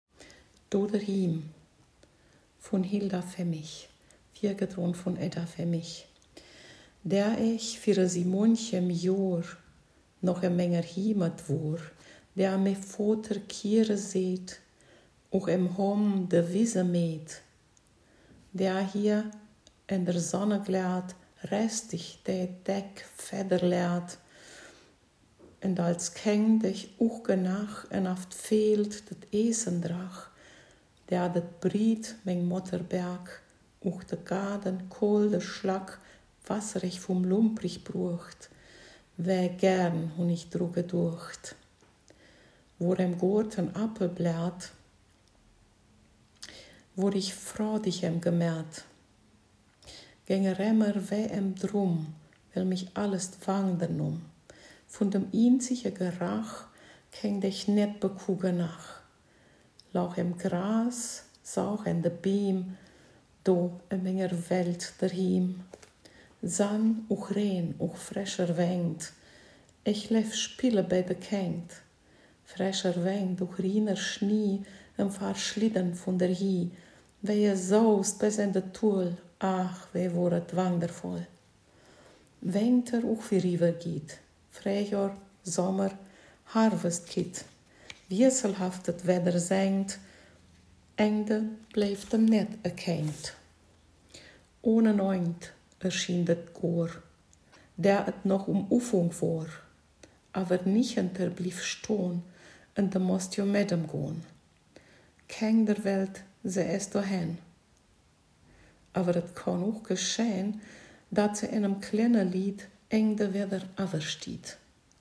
Ortsmundart: Hermannstadt